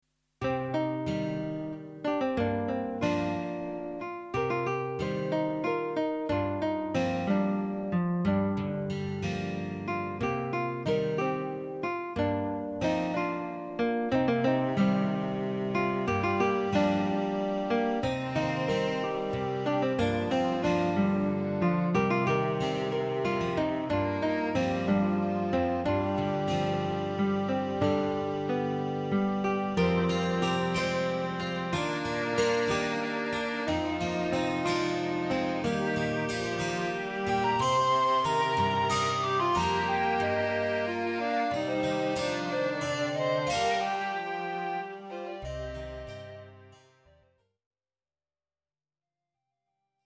Hierbij een aantal fragmenten op basis van inheemse en folkloristische instrumenten.
Downlands Britse folkmuziek 0:50 784kb 198?
Landelijk en rustiek